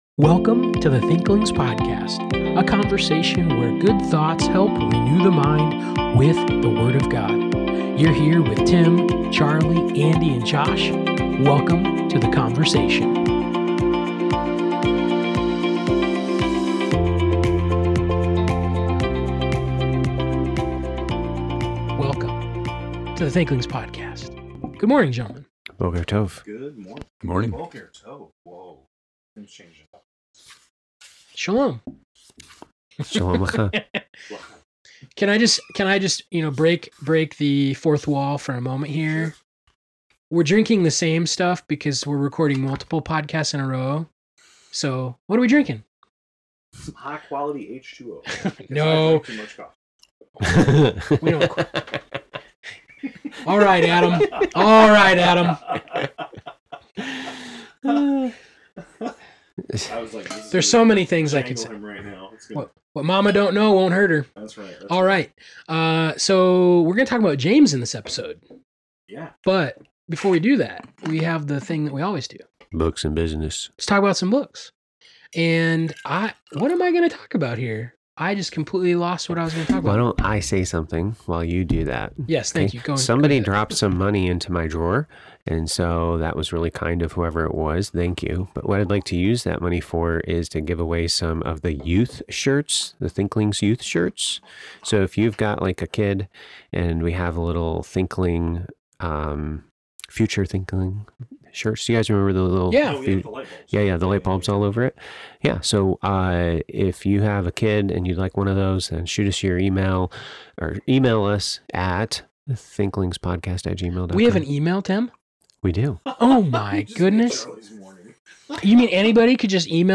a rich and thoughtful discussion on James 2. The team digs deep into Scripture, exploring faith, works, and the practical outworking of gospel truth.